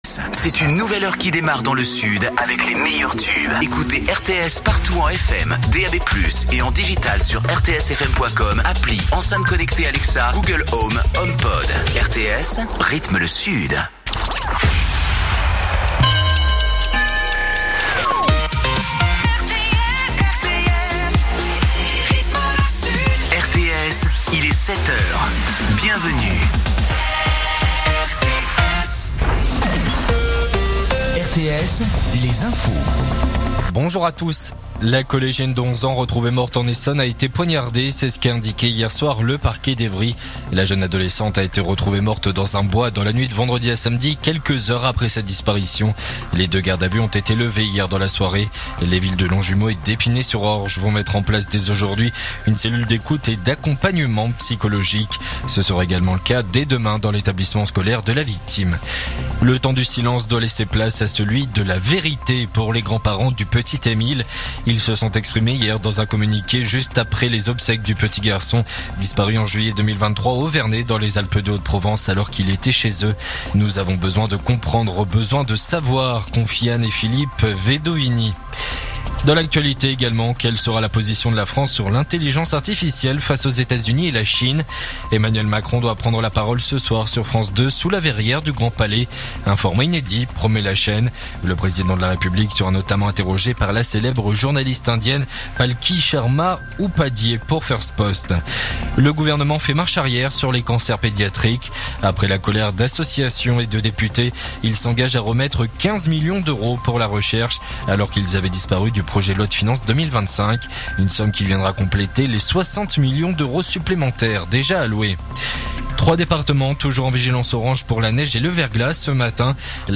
info_avignon_289.mp3